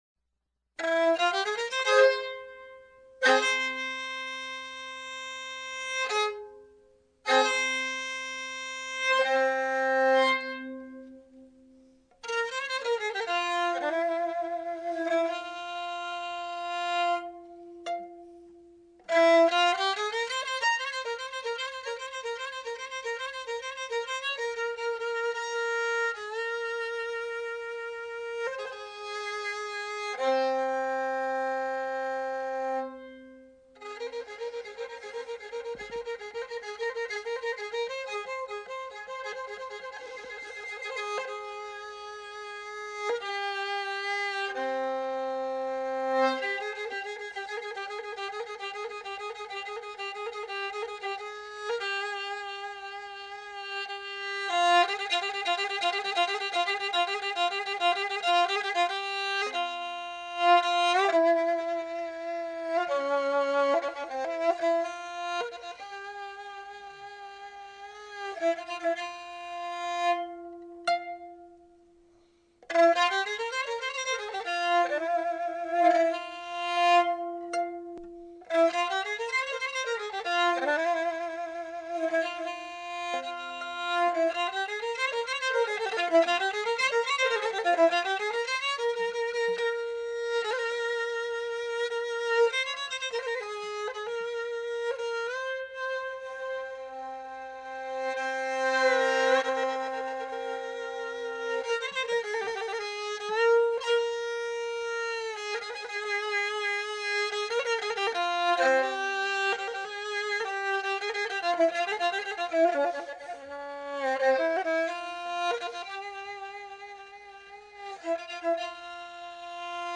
kamancha, tar, and oud